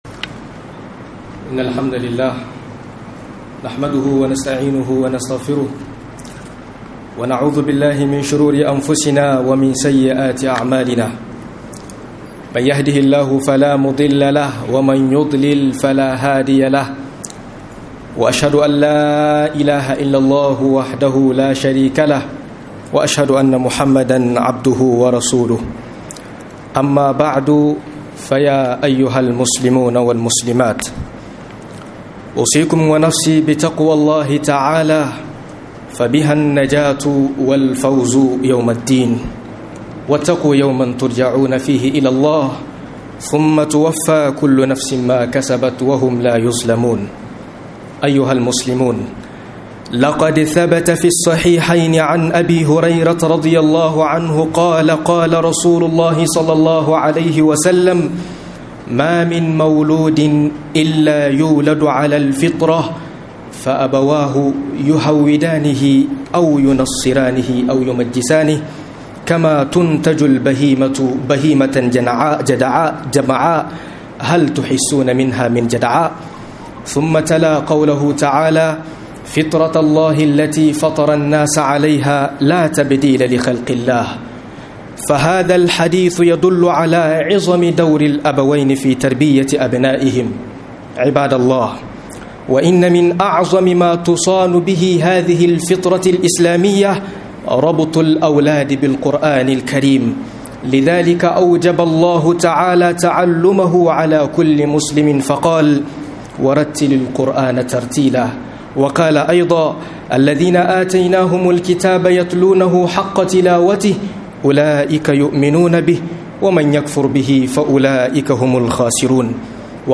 Mahimmancin Koyar da Yara Alkur'ani - MUHADARA